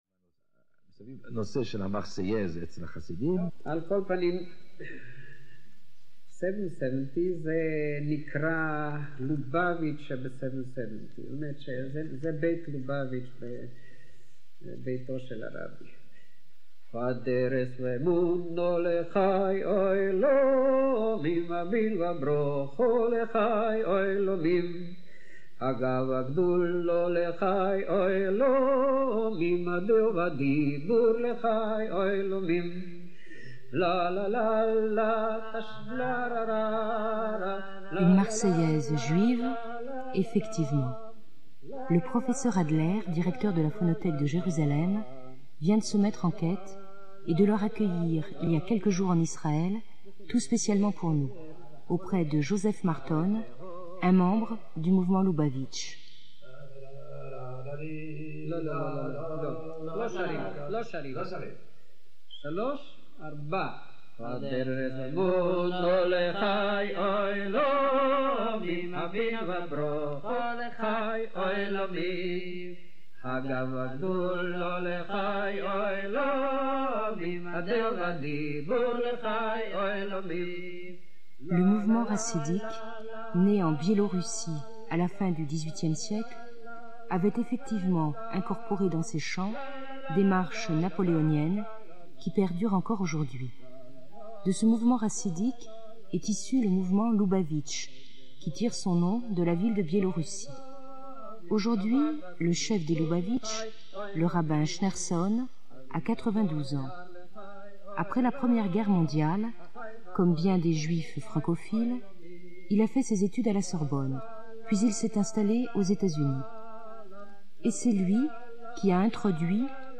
Audio 1: The Marseillaise sung by the Lubavitch. Excerpt from the program Nuits magnétiques